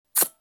The unique sound the Yashica atoron makes when the shutter is released sounds like
It comes from the blades, levers and gears.
Due to low speeds at the beginning and end, a noise is only audible for half of this time, i.e. 1/8 second.
atoron-woosh.mp3